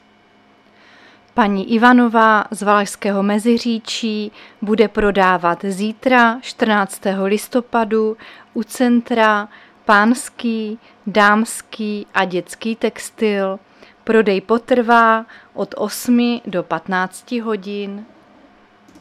Záznam hlášení místního rozhlasu 13.11.2024
Zařazení: Rozhlas